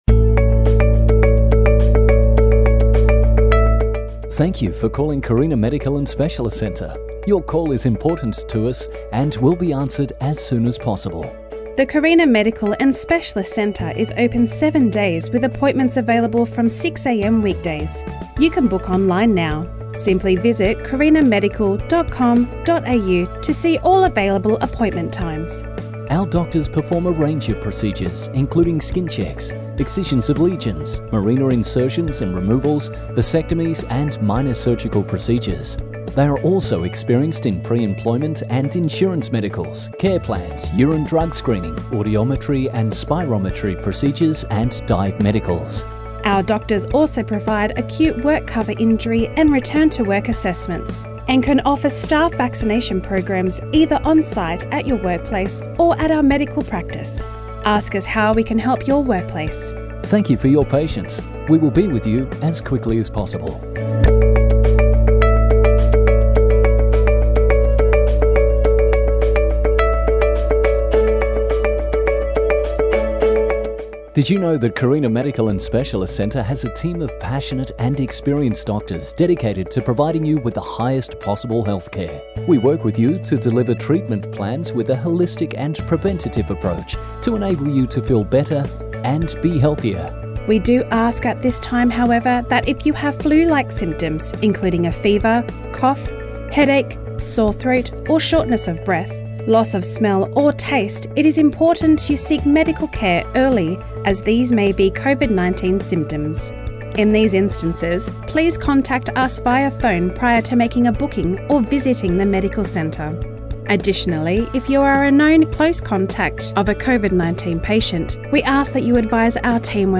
Recorded Voice Announcements